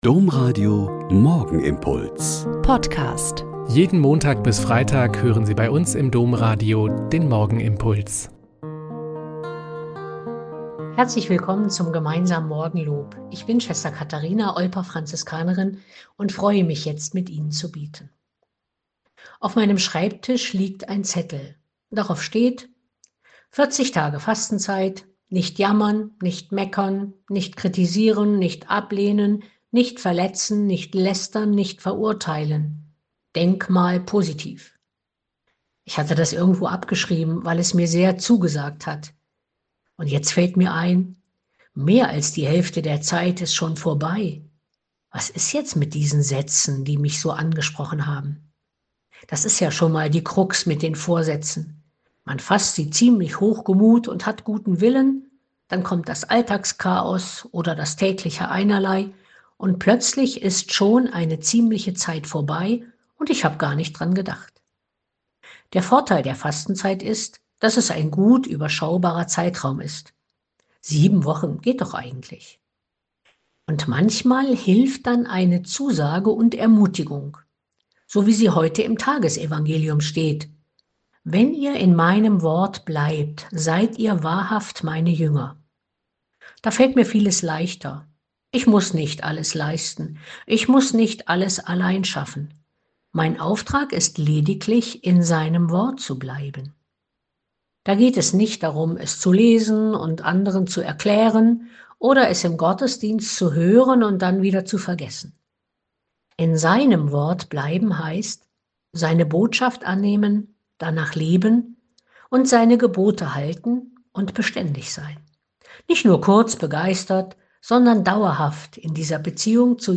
Lk 17,20-25 - Gespräch